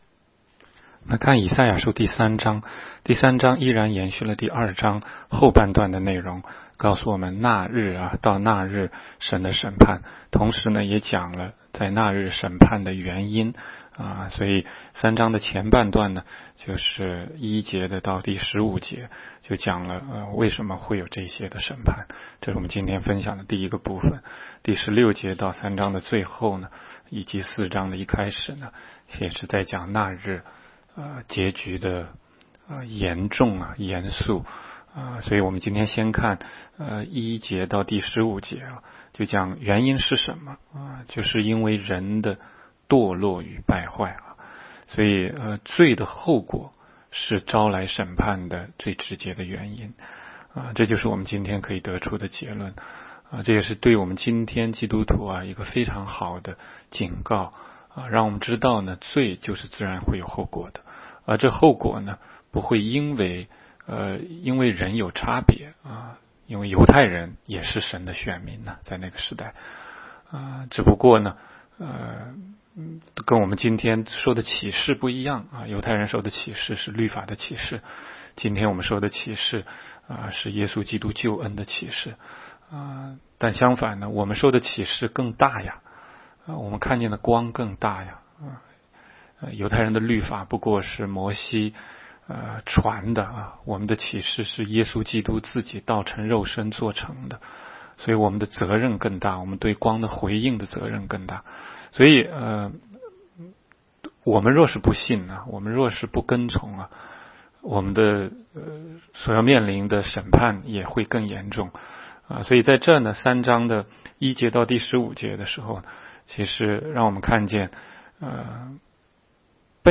16街讲道录音 - 每日读经 -《 以赛亚书》3章